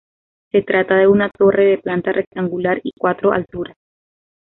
Uitgesproken als (IPA) /reɡtanɡuˈlaɾ/